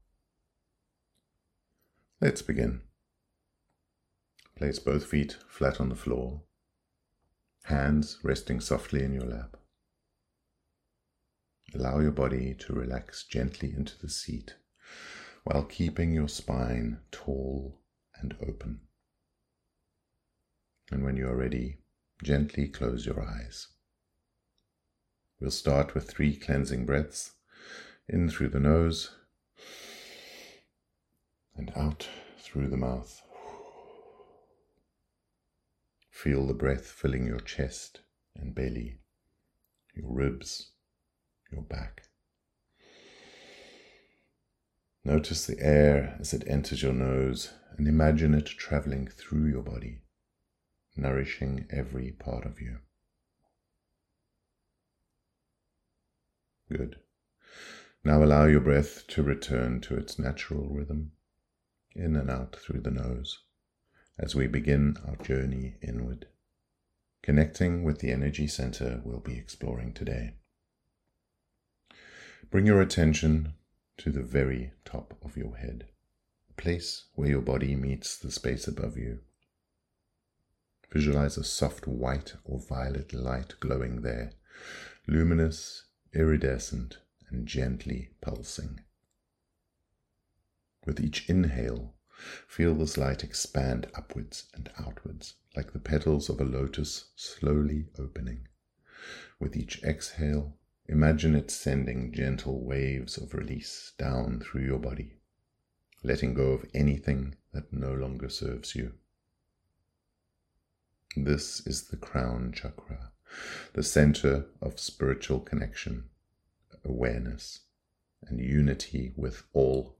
Crown Chakra Meditation
CH07-meditation.mp3